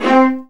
VIOLINS C4.wav